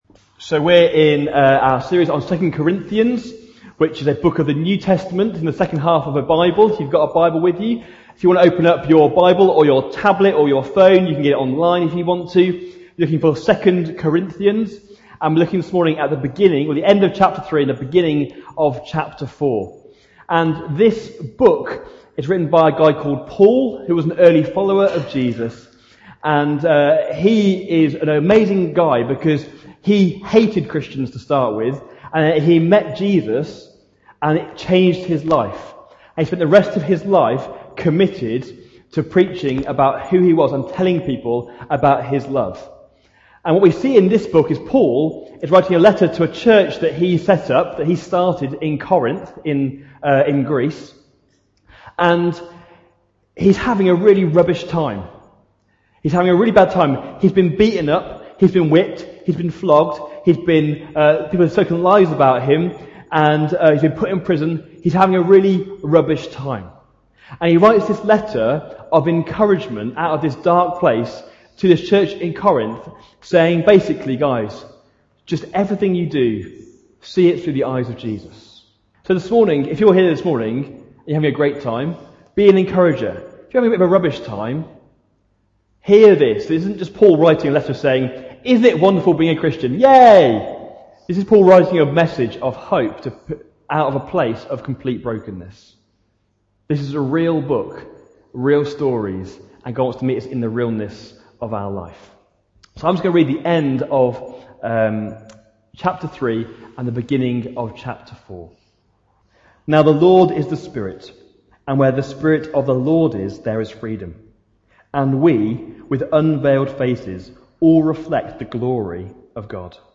Aug 19, 2018 Jars of Clay: Fills and Spills MP3 SUBSCRIBE on iTunes(Podcast) Notes Sermons in this Series This message explores the power of the presence of God in us, what we fill our lives with is what will spill out in the hard knocks of life.